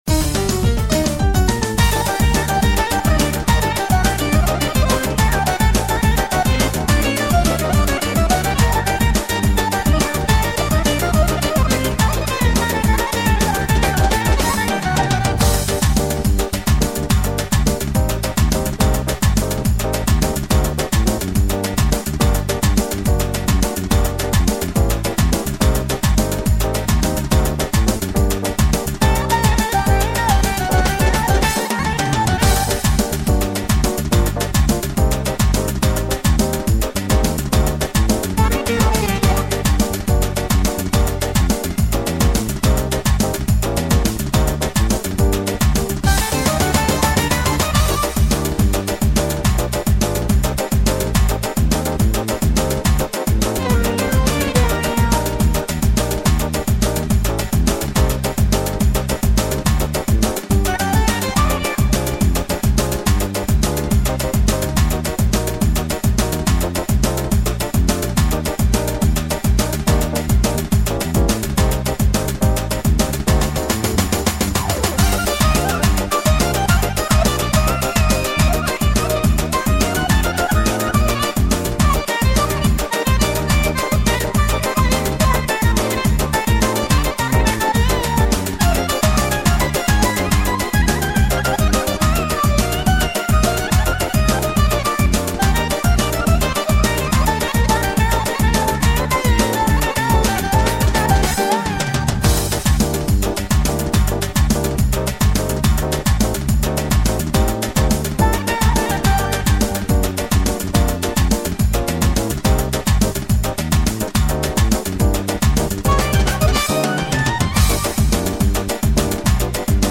минусовка версия 222